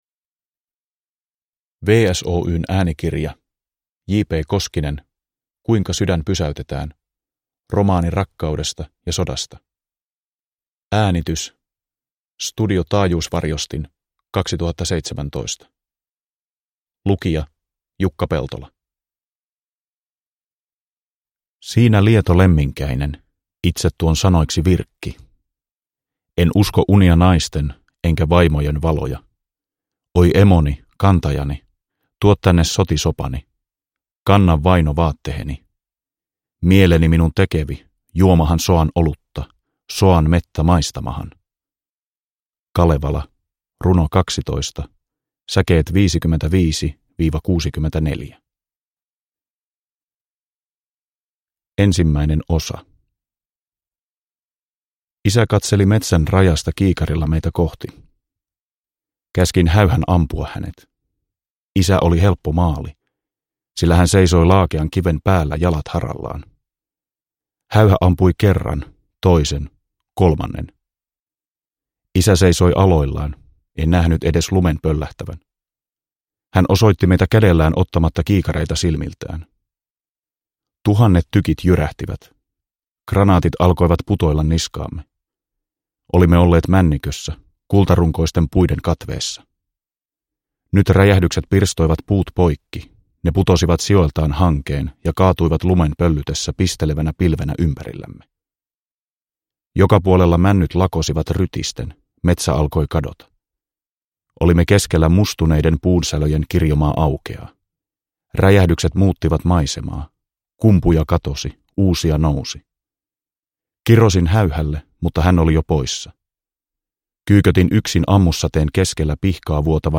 Kuinka sydän pysäytetään (ljudbok) av Juha-Pekka Koskinen